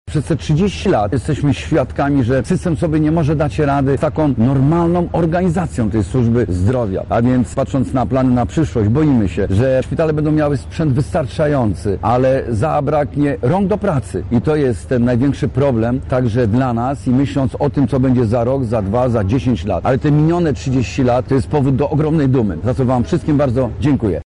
• mówi Jurek Owsiak.